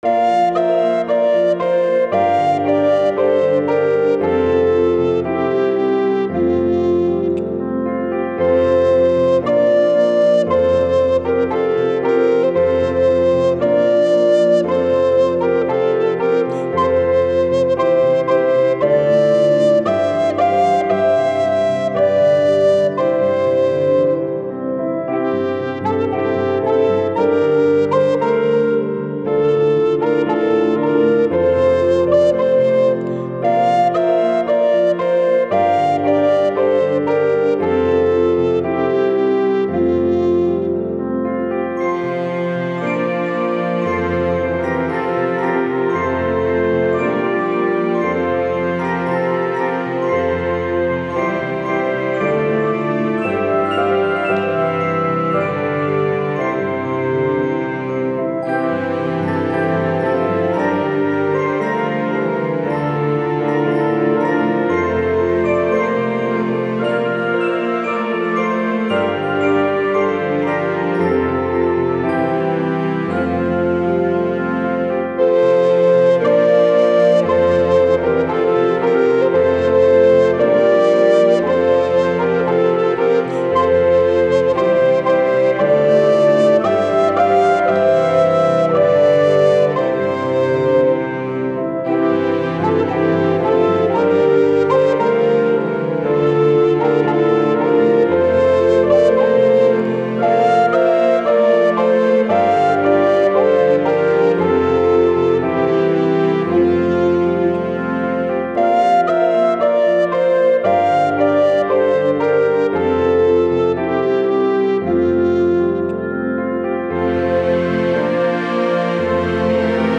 GEMA-freie Musik